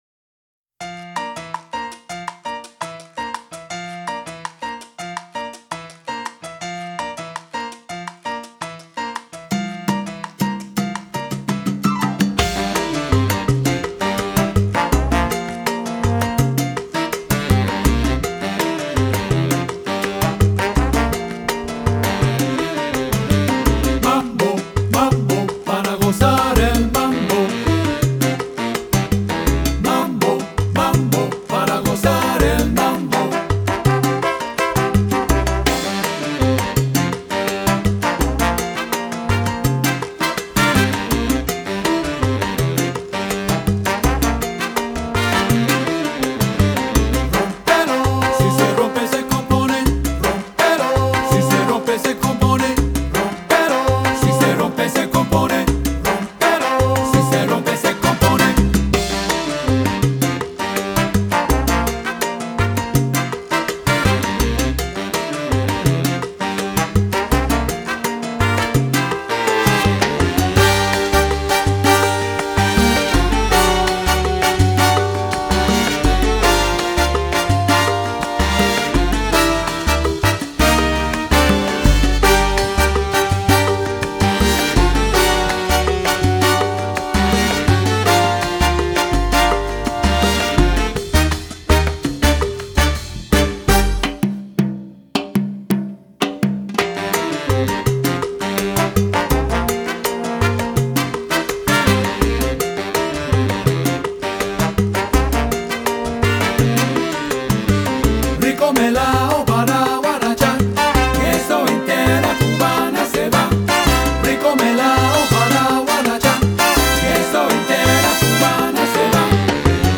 mixing in elements of funk and jazz to create a unique edge.